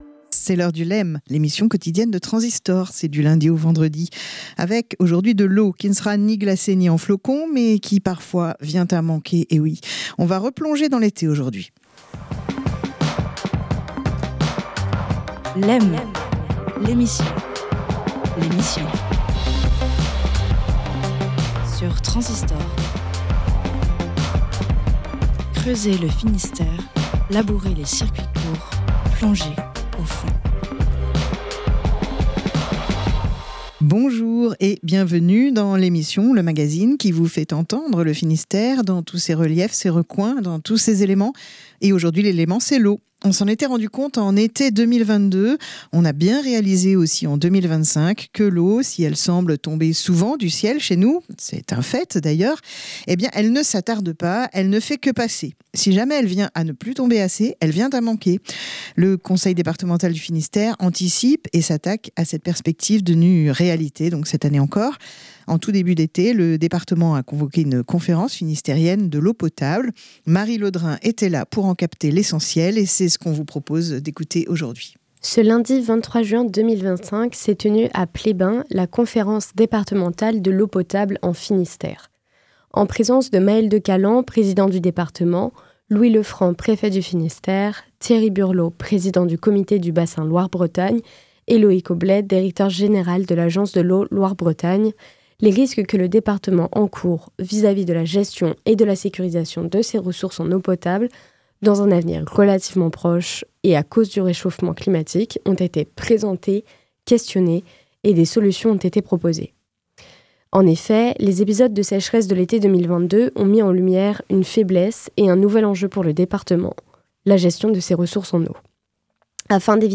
Lundi 23 juin 2025 a eu lieu à Pleyben la conférence départementale de l’eau potable en Finistère, à l'initiative du Conseil départemental, avec la préfecture et l'agence de l'eau Loire-Bretagne. Dans un contexte de réchauffement climatique, il s'agit en effet d'anticiper les risques de sécheresse et de manque d'eau potable.
Maël De Calan, Président du Département, Louis Le Franc, Préfet du Finistère, Thierry Burlot, Président du Comité du bassin Loire-Bretagne, et Loïc Obled, Directeur général de l’Agence de l’eau Loire-Bretagne, ont fait le point sur les risques que le département encourt pour la gestion et de la sécurisation de ses ressources en eau potable à l’avenir, du fait du réchauffement climatique.